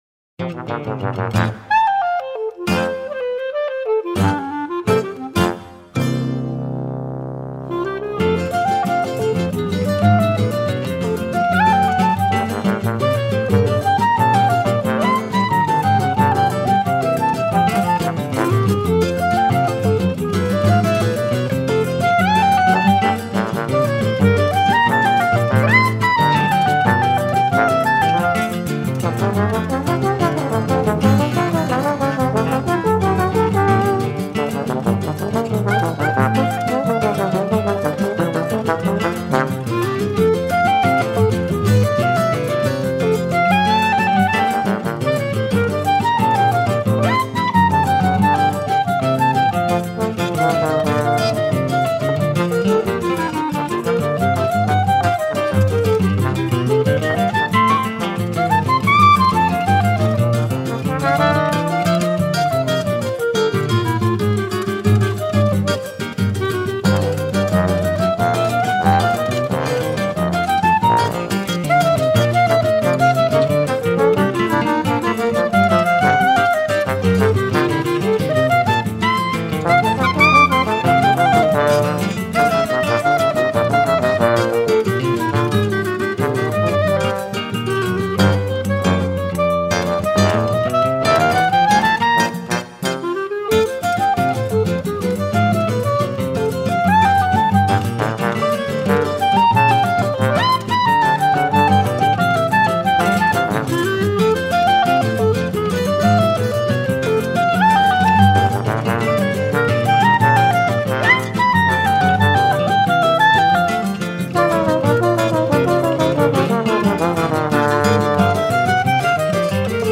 03:43:00   Instrumental